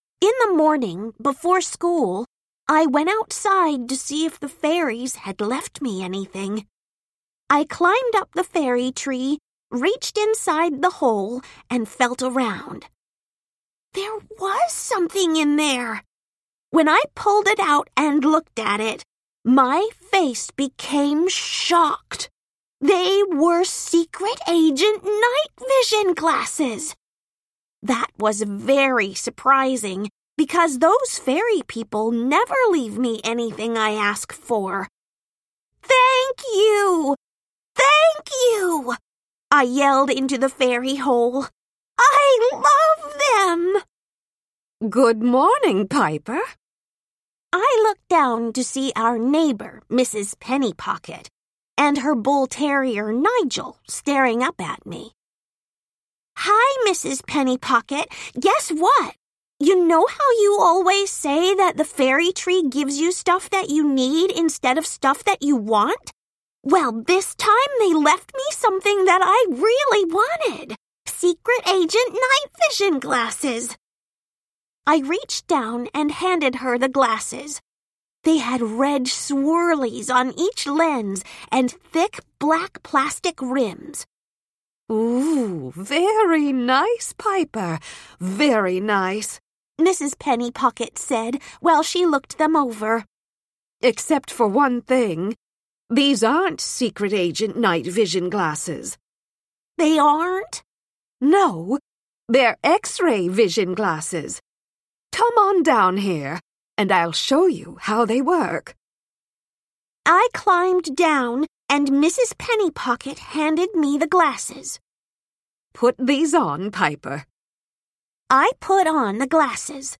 Middle Grade Audiobooks